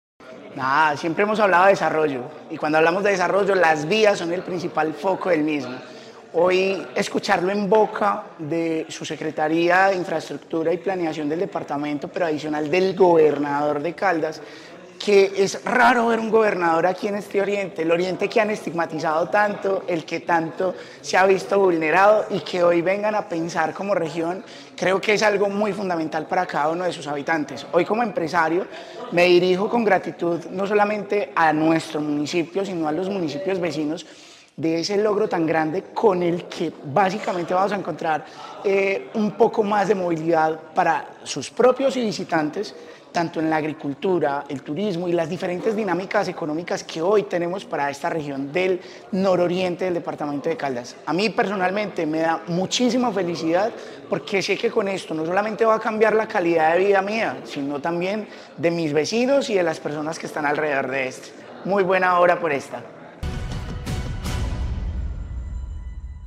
empresario